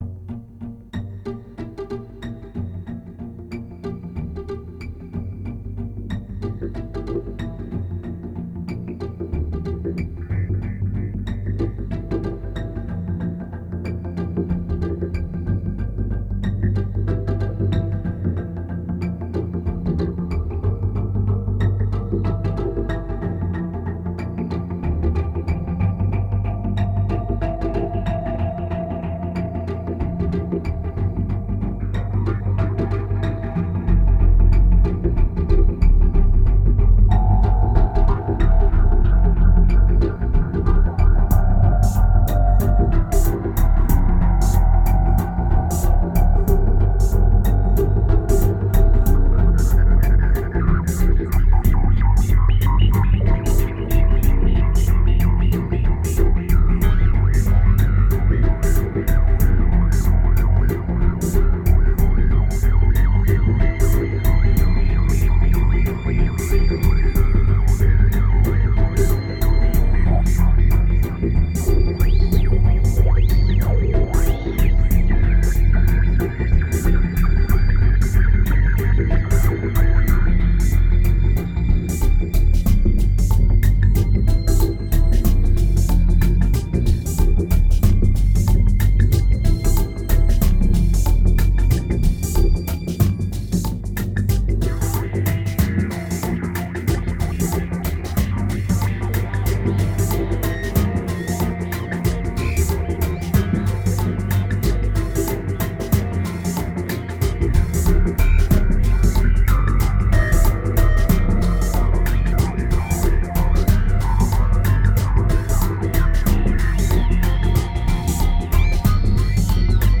It's only autumn but the mood is already pretty somber.
1847📈 - 66%🤔 - 93BPM🔊 - 2010-10-04📅 - 88🌟